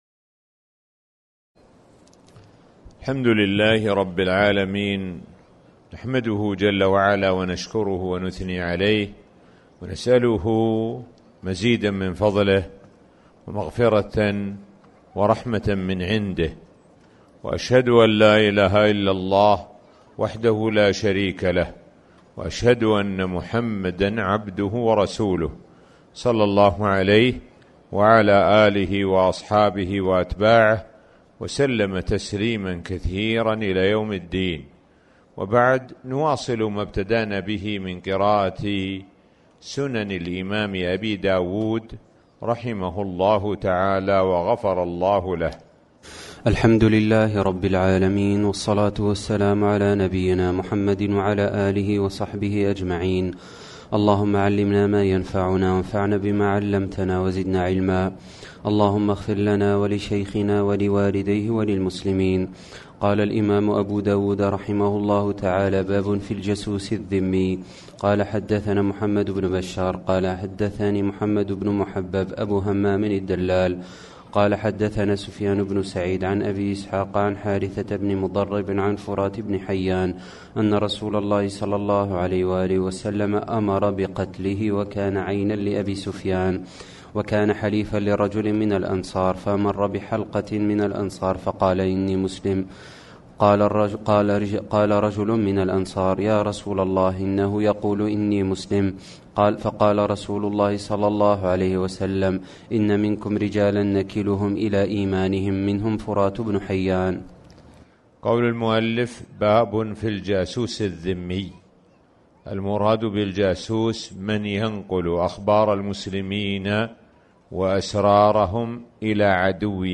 تاريخ النشر ١١ رمضان ١٤٣٩ هـ المكان: المسجد الحرام الشيخ: معالي الشيخ د. سعد بن ناصر الشثري معالي الشيخ د. سعد بن ناصر الشثري كتاب الجهاد